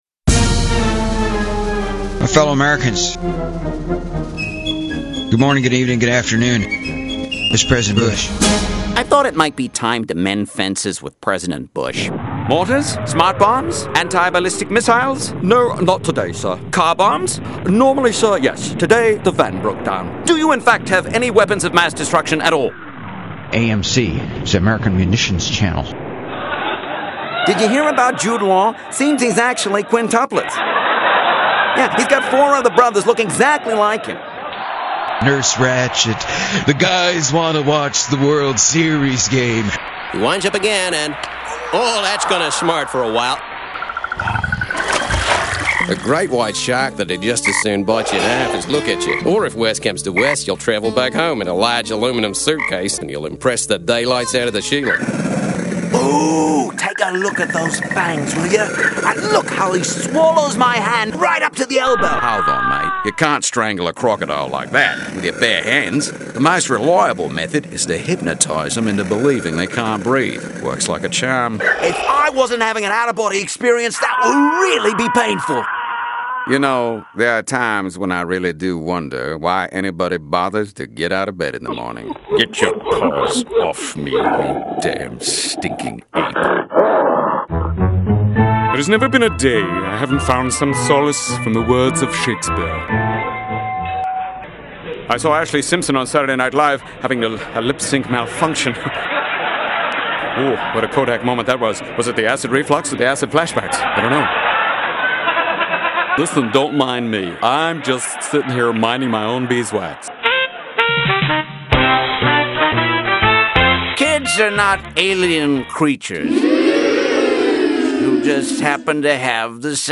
Below is a small sampling of voices